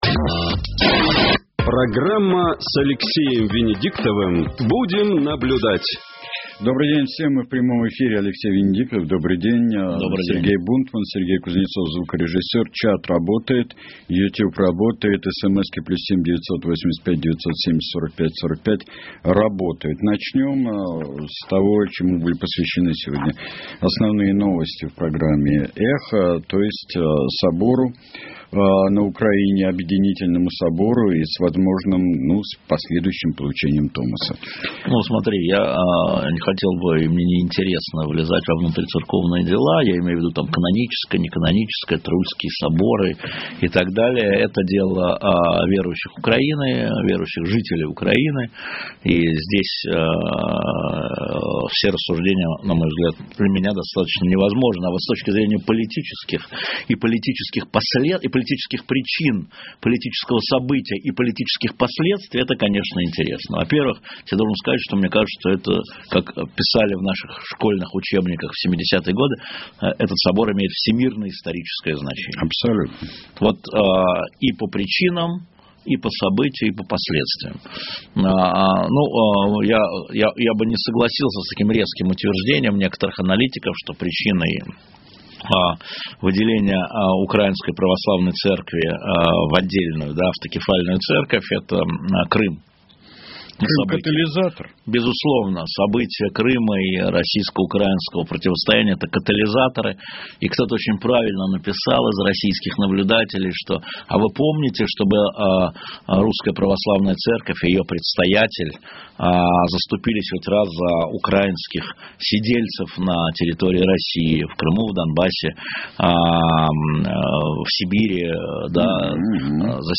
Мы в прямом эфире.